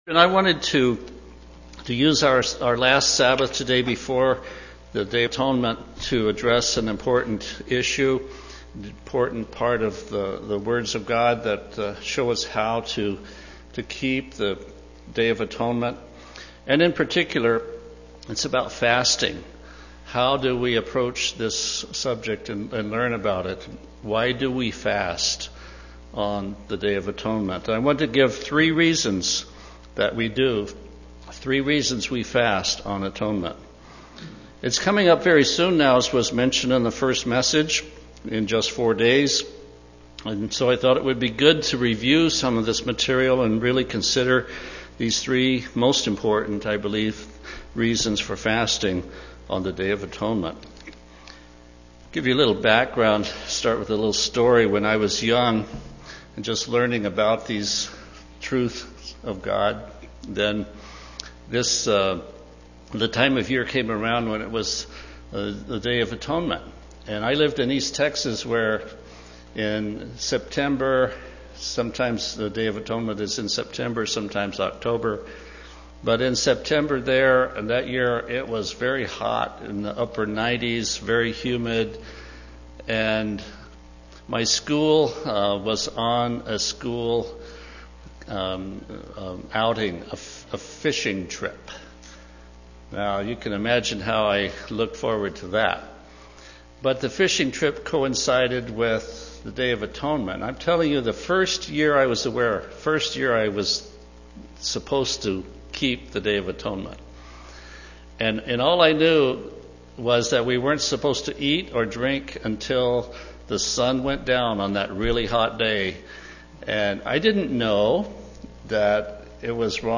Given in Olympia, WA
UCG Sermon Studying the bible?